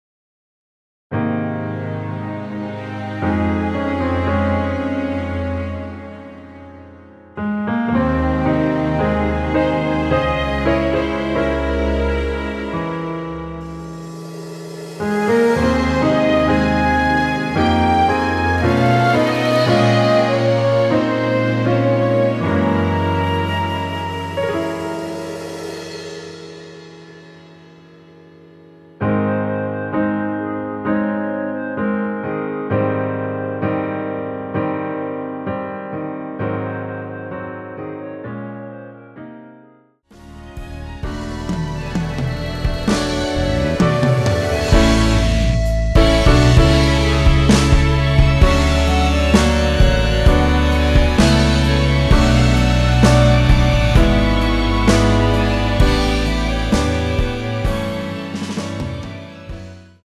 원키에서(-1)내린 멜로디 포함된 MR입니다.
Ab
앞부분30초, 뒷부분30초씩 편집해서 올려 드리고 있습니다.
중간에 음이 끈어지고 다시 나오는 이유는